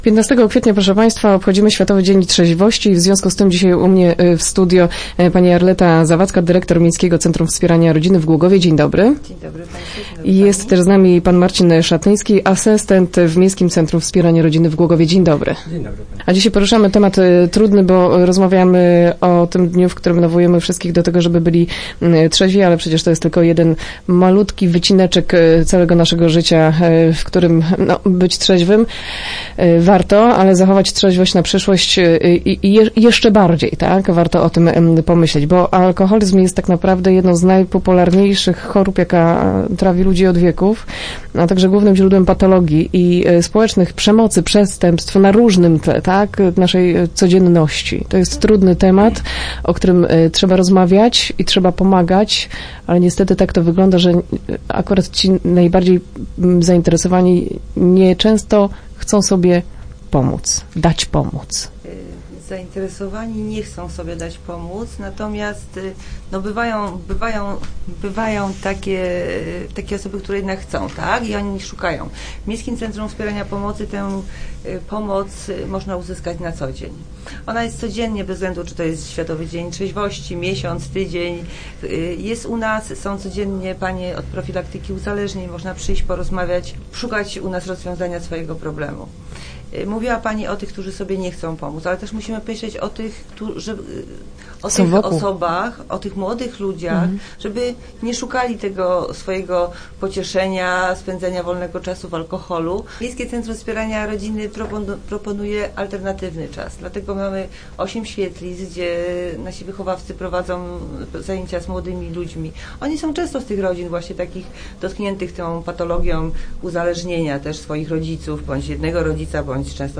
Start arrow Rozmowy Elki arrow Życie na trzeźwo